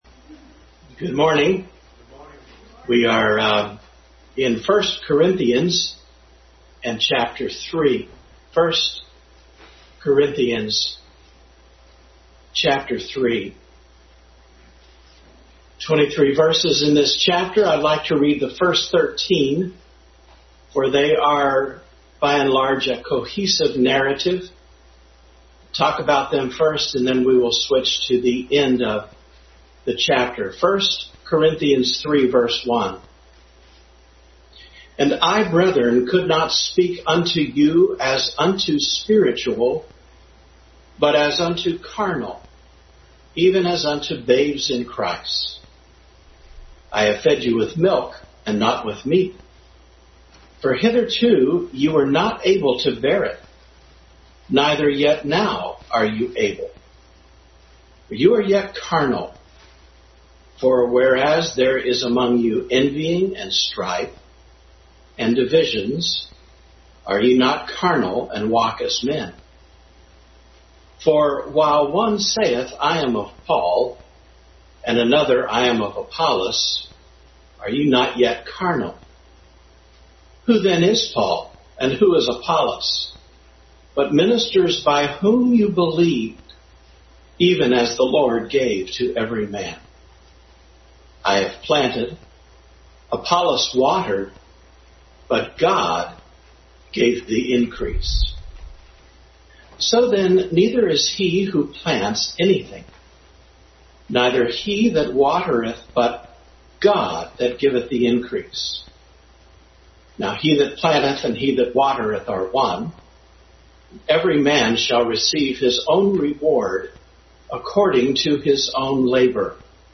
Bible Text: 1 Corinthians 3:1-23 | Adult Sunday School continued study in 1 Corinthians.
1 Corinthians 3:1-23 Service Type: Sunday School Bible Text